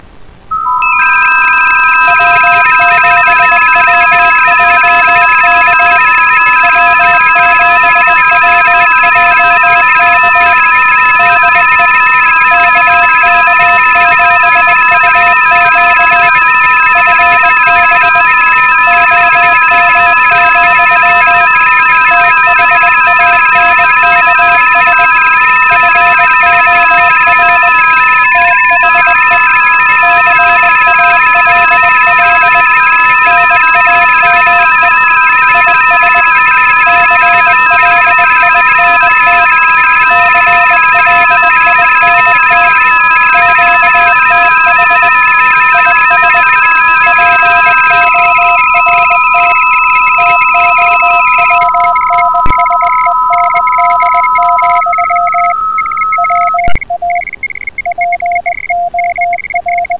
Ecco di seguito come suonano i vari modi:
guazzabuglio di suoni digitali